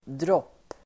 Uttal: [dråp:]